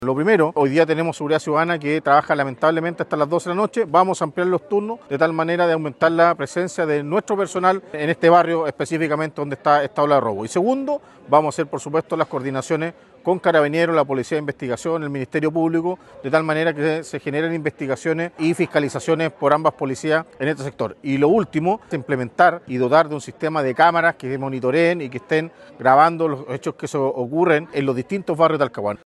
Luego de una reunión sostenida por el alcalde de la comuna puerto Eduardo Saavedra, con los vecinos del sector, es que el edil anunció acciones para aumentar la seguridad del sector y la comuna.
alcalde-saavedra.mp3